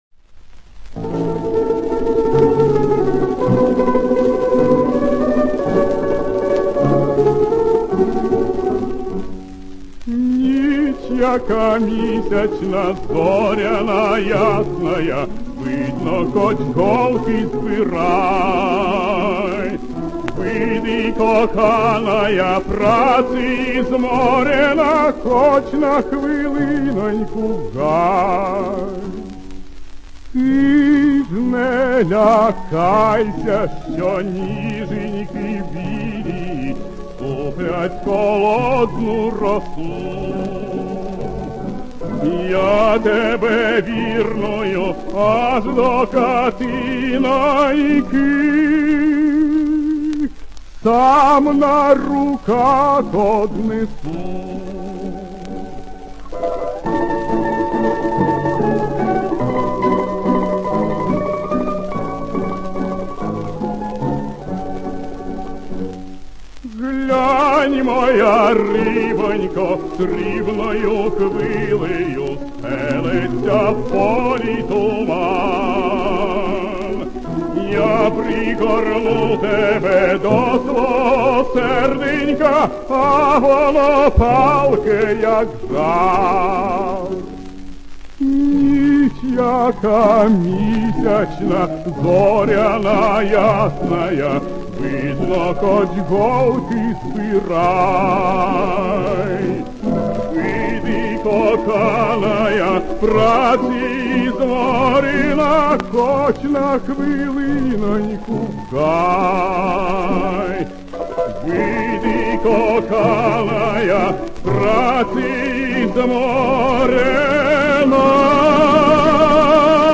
в сопровождении ансамбля народных инструментов
Романс «Hiч яка мiсячна» в исполнении Андрея Иванова.